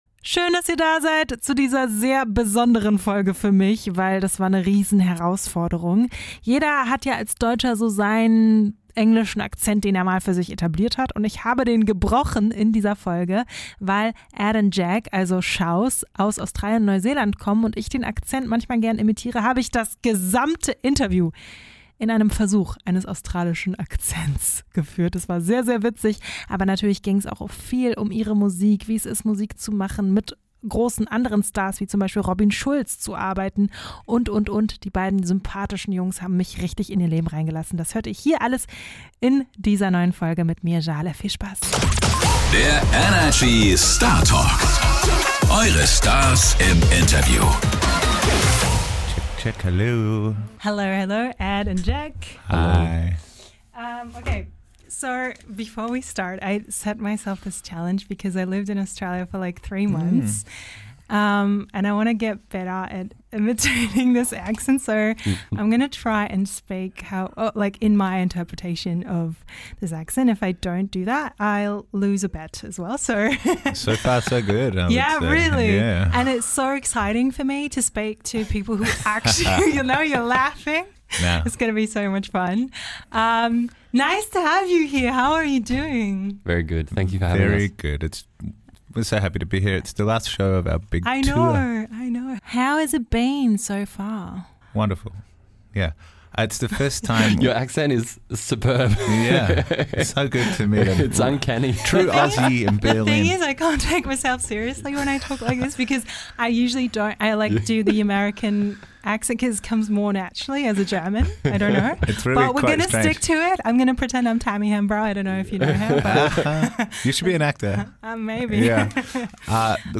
Sie versucht, das komplette Interview über im australischen Akzent zu sprechen.